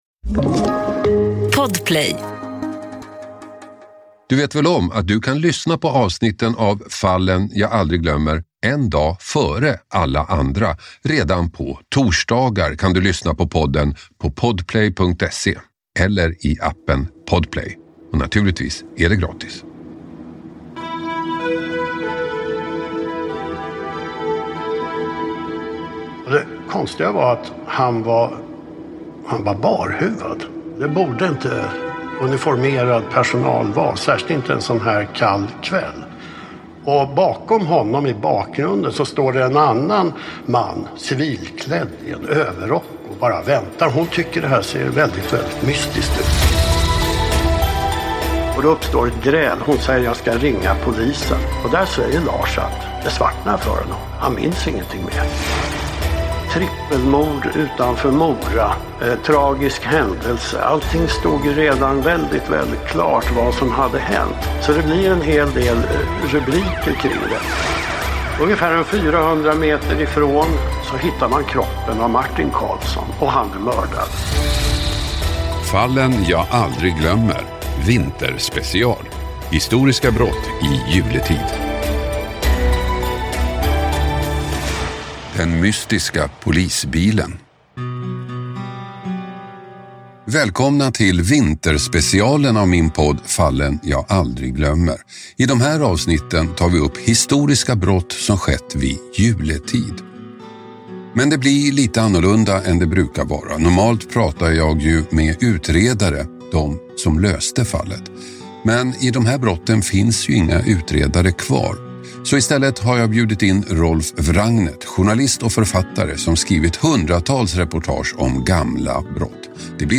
Hasse Aro får sällskap i studion